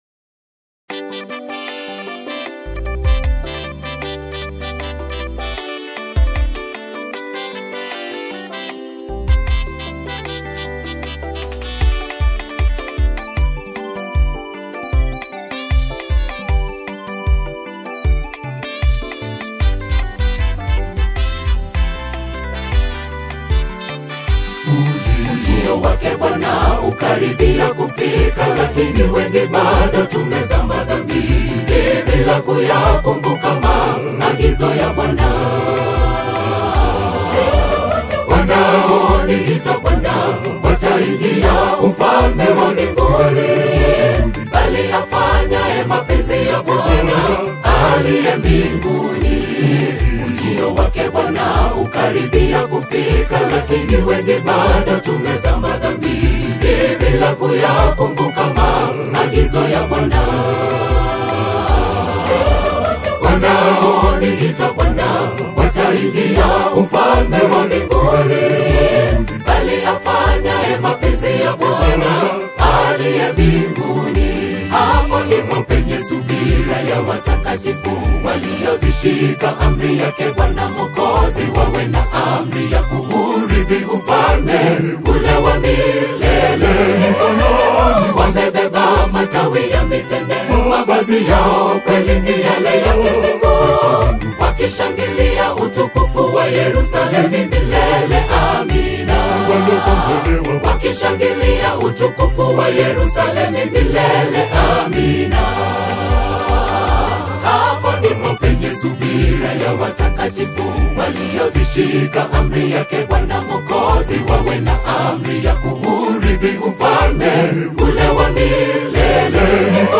a majestic and awe-inspiring single
choral ensembles